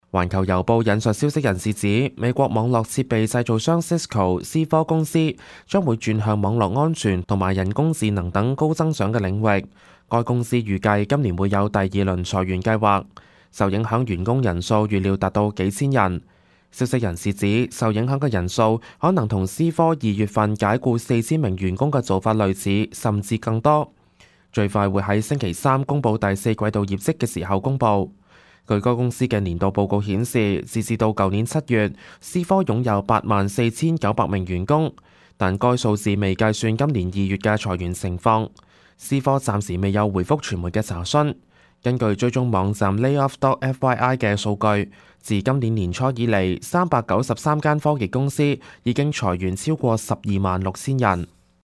news_clip_20105.mp3